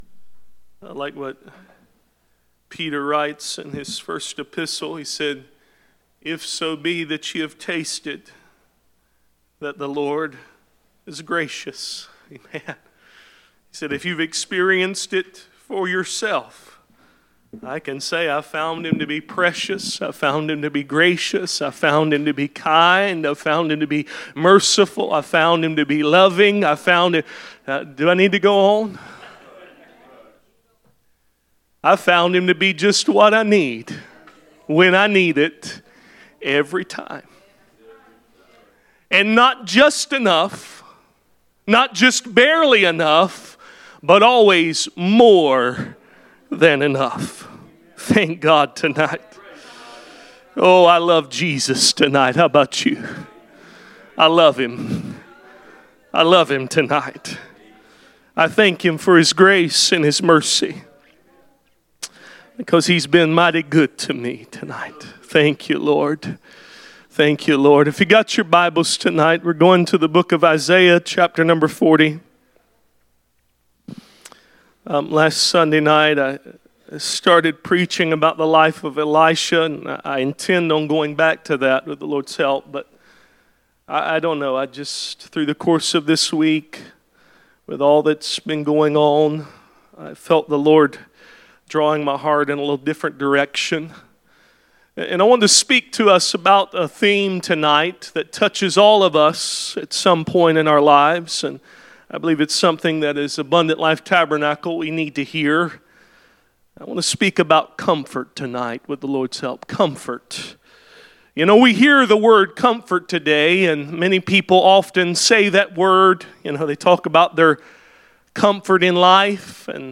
Passage: Isaiah 40:1-11 Service Type: Sunday Evening « Church Attendance